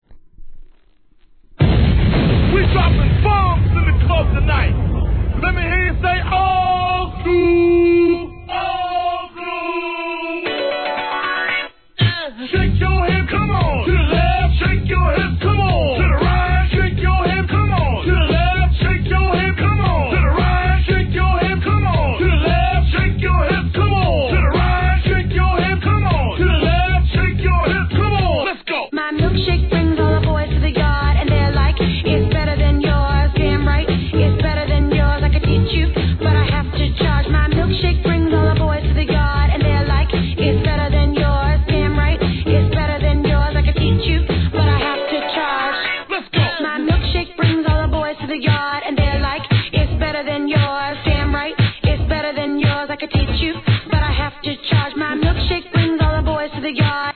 HIP HOP/R&B
OLD SCHOOL FLAVORたっぷりのB面がお勧めっ!!!